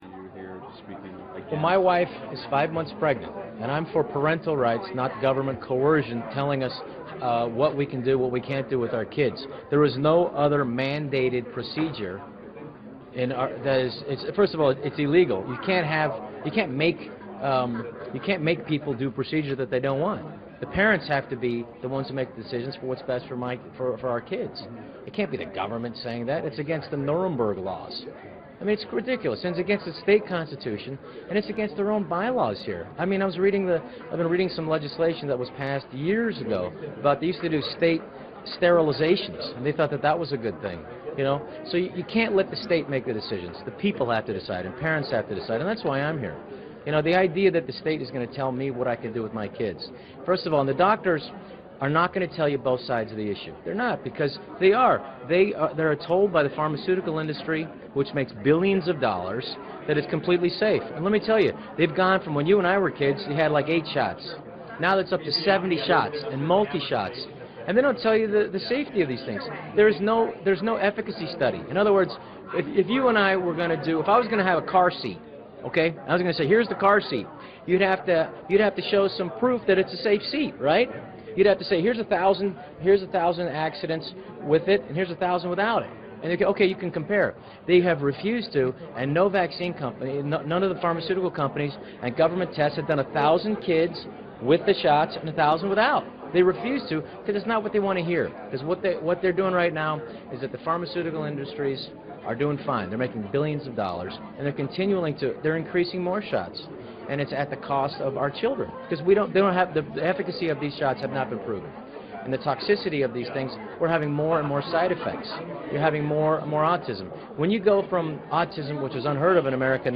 השחקן הקומיקאי רוב שניידר מדבר נגד חיסונים